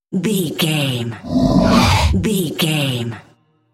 Whoosh creature growl fast
Sound Effects
Atonal
scary
ominous
eerie
whoosh